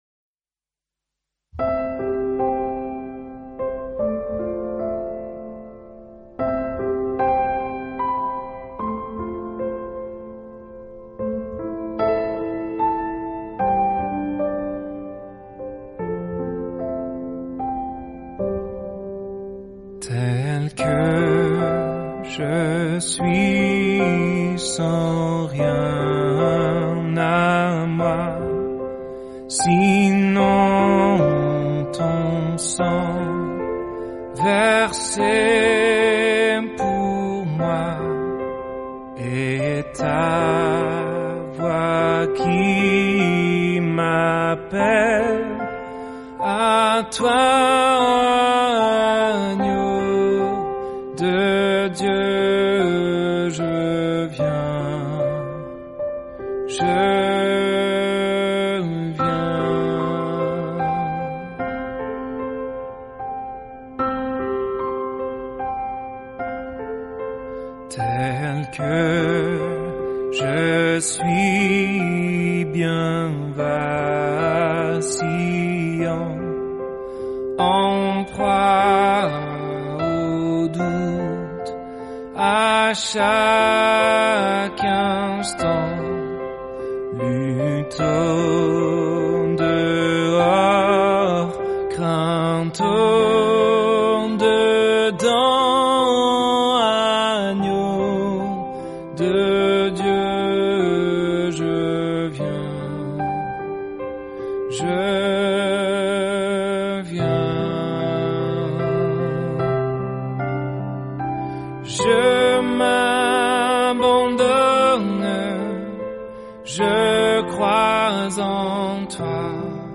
Je t’encourage à prolonger ce moment de dévotion par un temps de louange, avec le chant “Tel que je suis” ci-dessous.